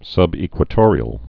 (sŭbē-kwə-tôrē-əl, -ĕk-wə-)